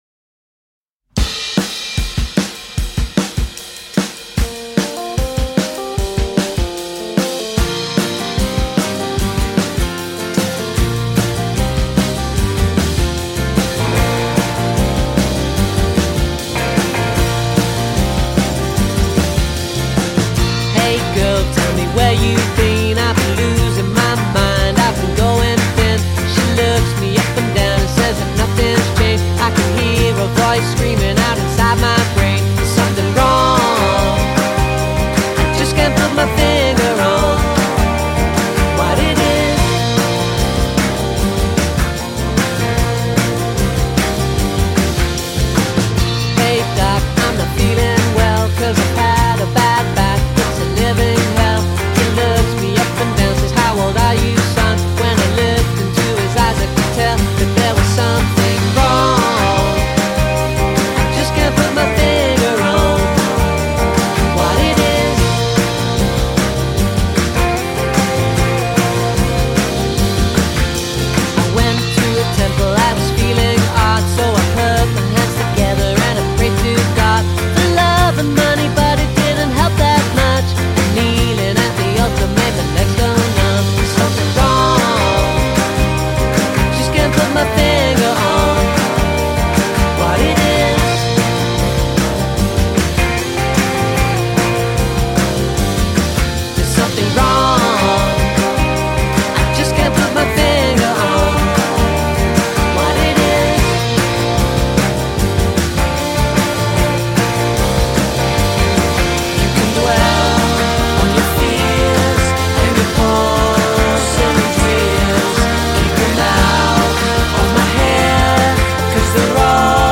international indie folk pop band